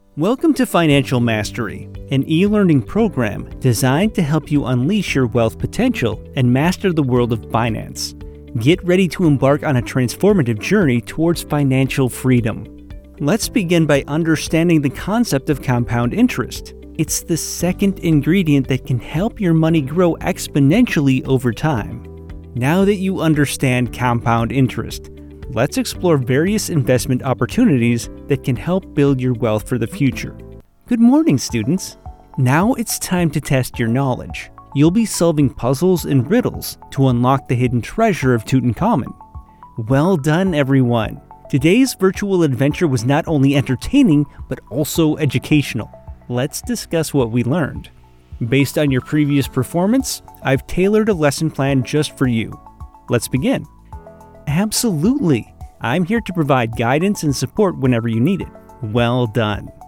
Male
Yng Adult (18-29), Adult (30-50)
E-Learning
E-Learning Demo